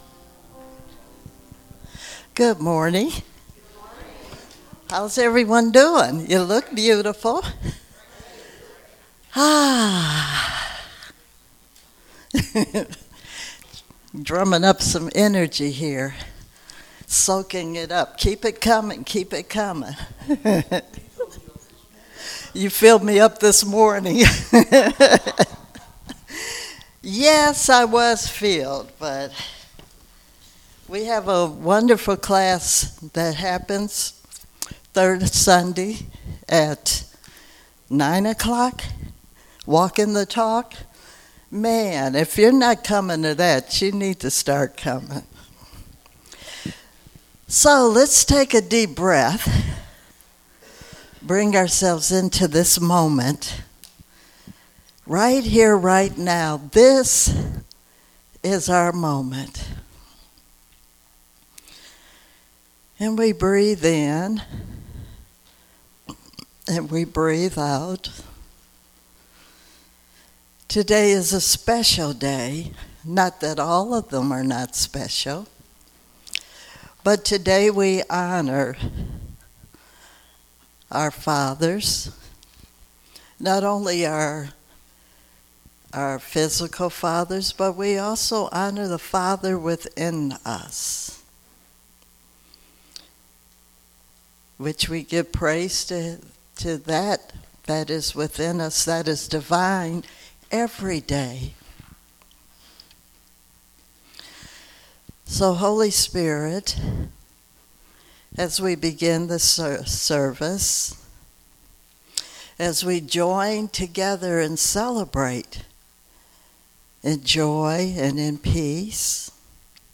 Spiritual Leader Series: Sermons 2023 Date